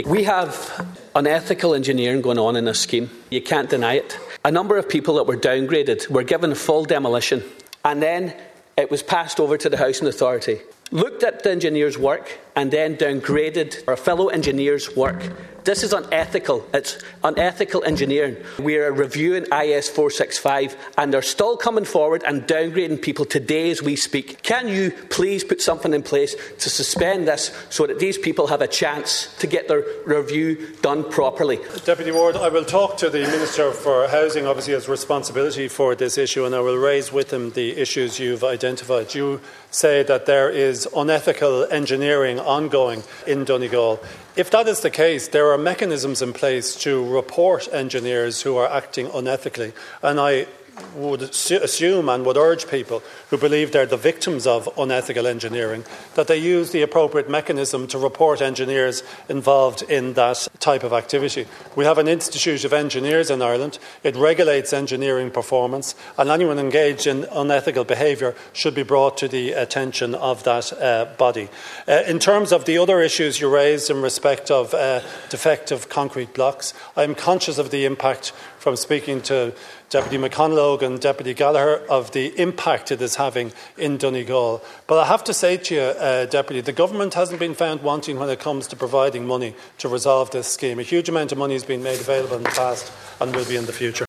Donegal Deputy Charles Ward told the Dail 40% of impacted homeowners are living with severe depression, while the Government continues to claim people are being looked after.